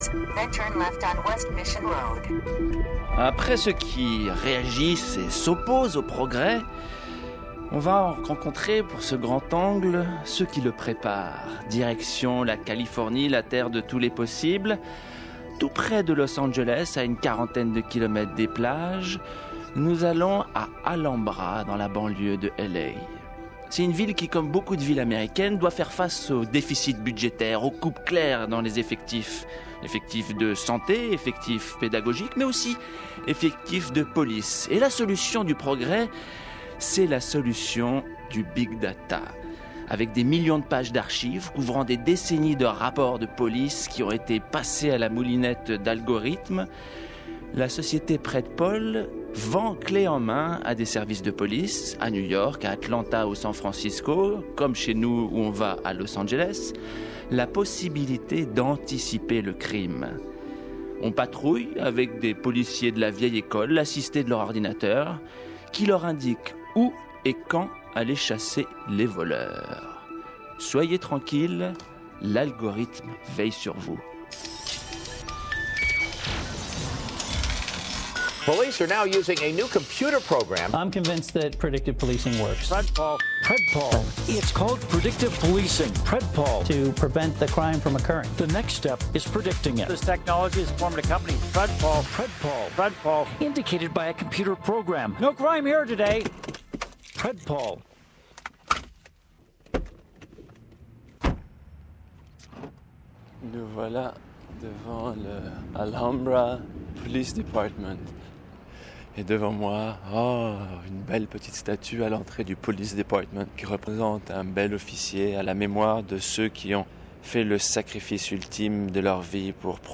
Avec des millions de pages d’archives couvrant des décennies de rapports de police, la société Predpol (pour PREDictive POLicing) a mis au point un algorithme prédictif, qui promet d’arrêter les criminels avant qu’ils n’aient pu commettre leur crime. Entre fantasmes à la Minority Report et obsession du chiffre, reportage à Alhambra, dans la banlieue de Los Angeles.